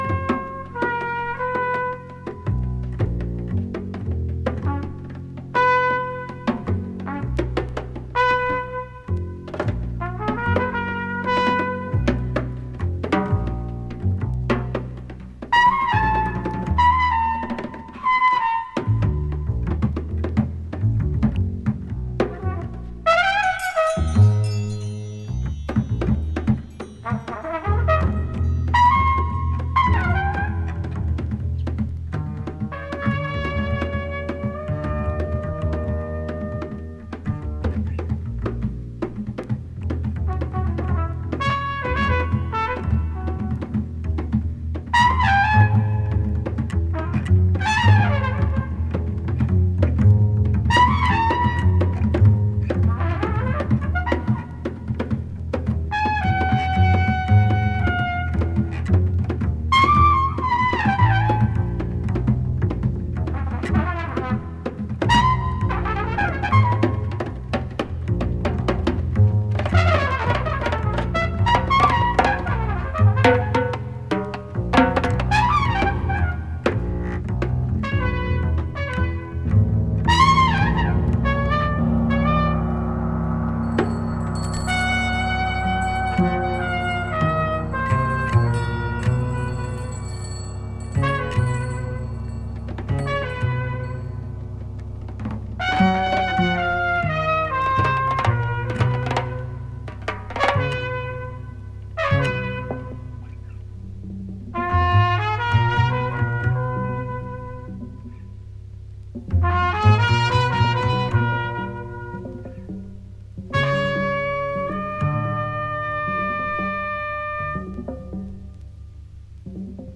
Gravado em 1979, em Paris
percussão
trompete, corneta, flauta e percussão